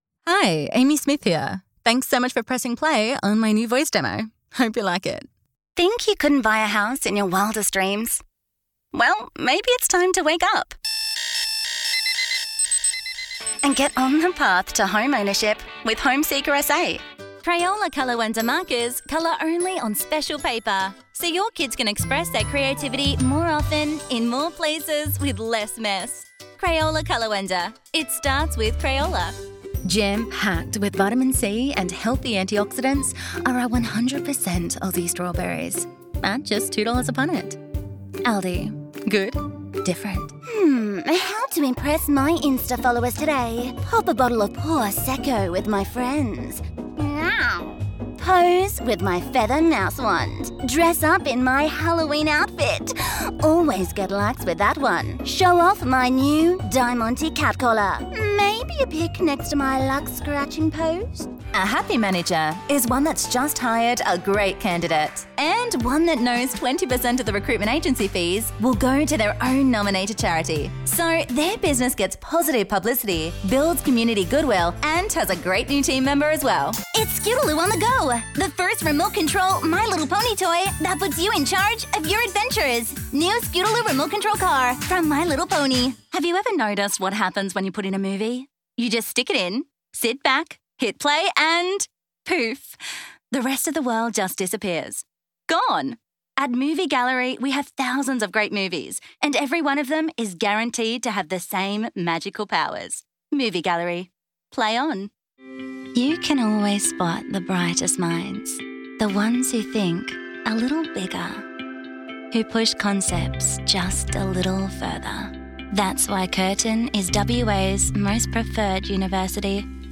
Sprechprobe: Werbung (Muttersprache):
Commercials